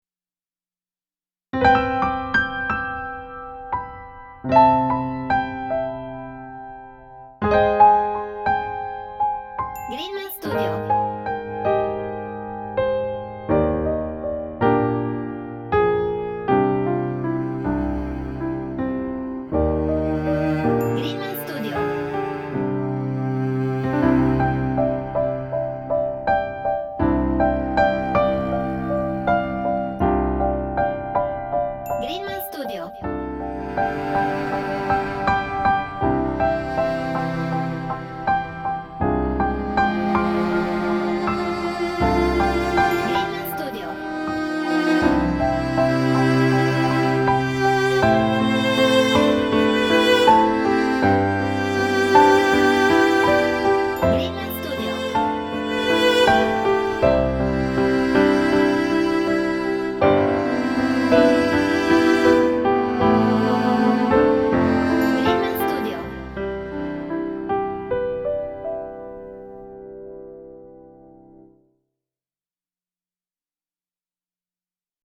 Relaxed/Romantic